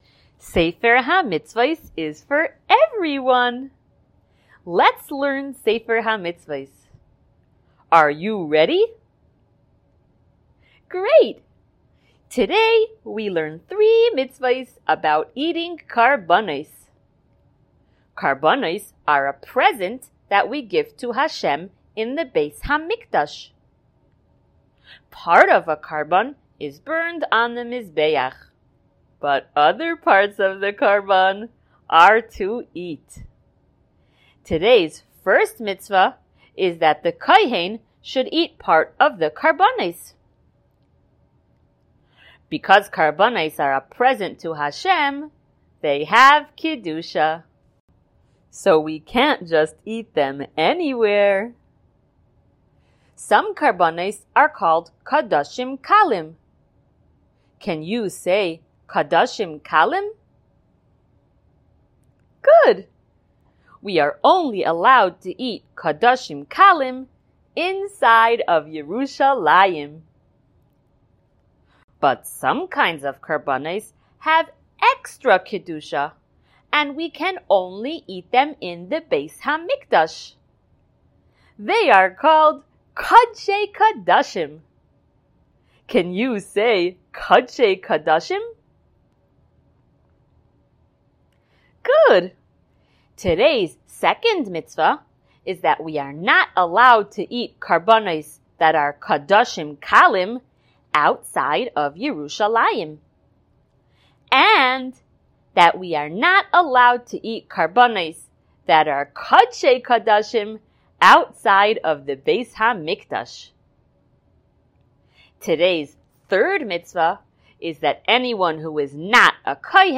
SmallChildren_Shiur161.mp3